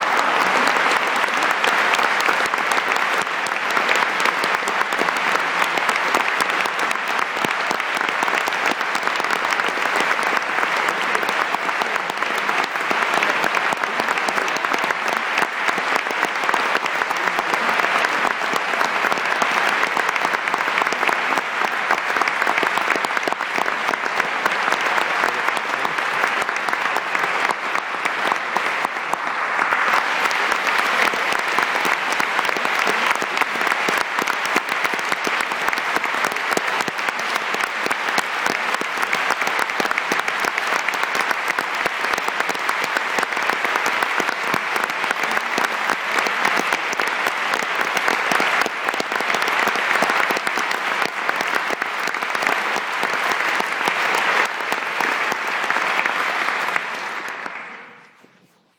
Sound_Effects_Applause.mp3